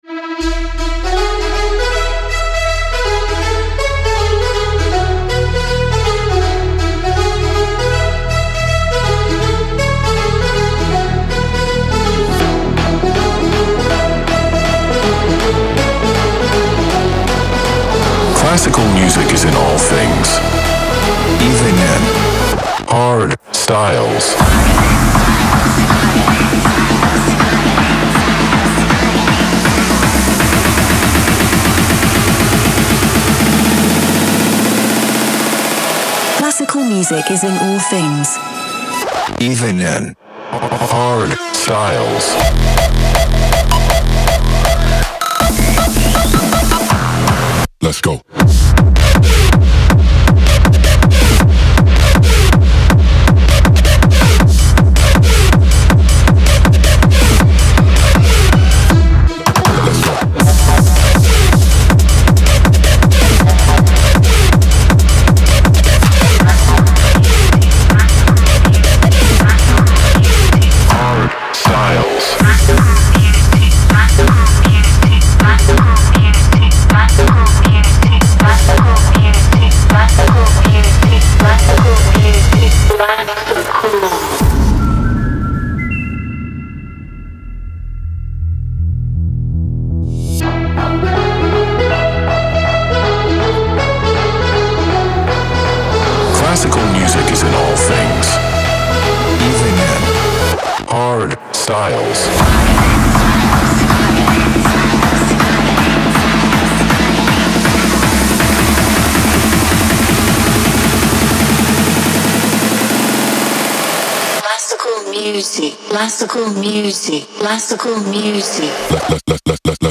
• Жанр: Hardstyle